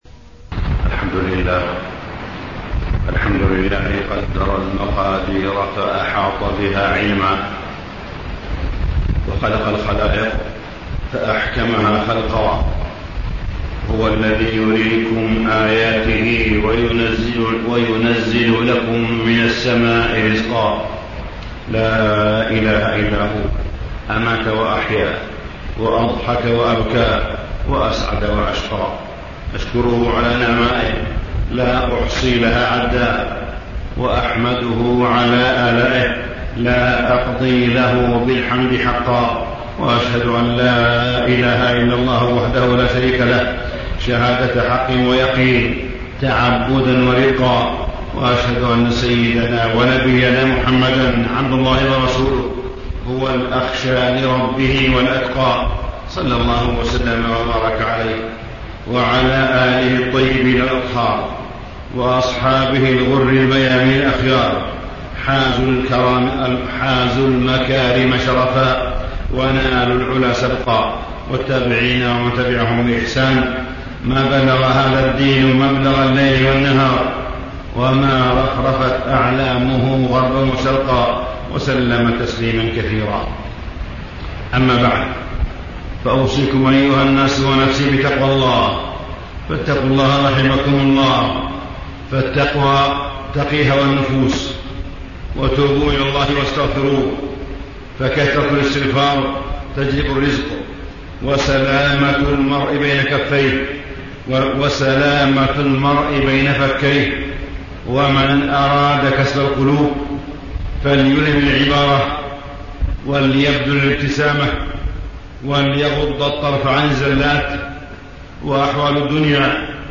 تاريخ النشر ١٥ شعبان ١٤٣٥ هـ المكان: المسجد الحرام الشيخ: معالي الشيخ أ.د. صالح بن عبدالله بن حميد معالي الشيخ أ.د. صالح بن عبدالله بن حميد وقفات مع الإصلاح والمصلحين